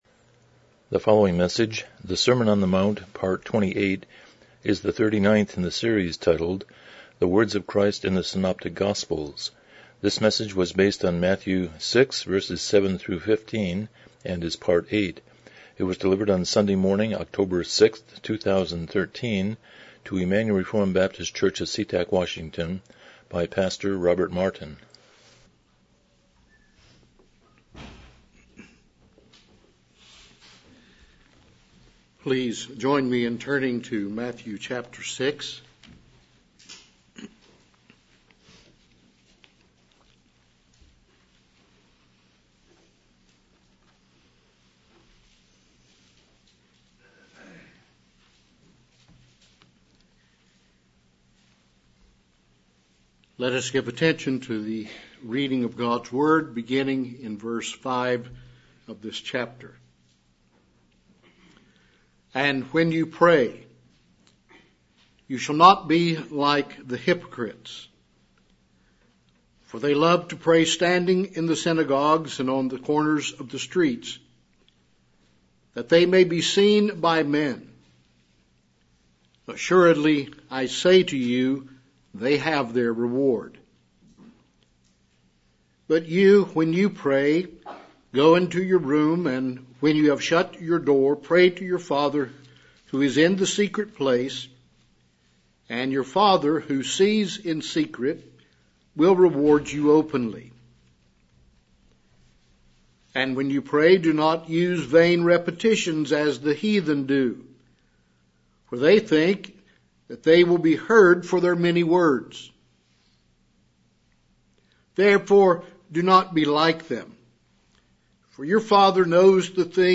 Passage: Matthew 6:7-18 Service Type: Morning Worship